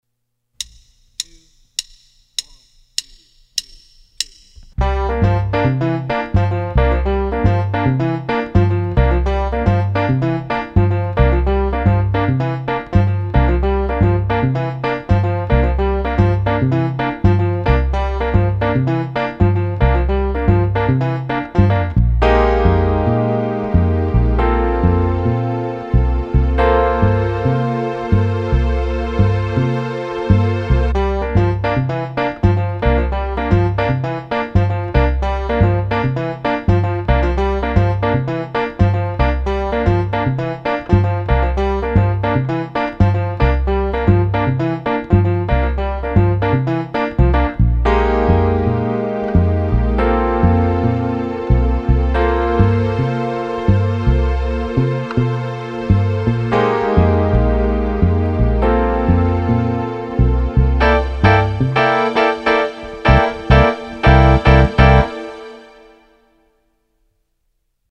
"Mambo"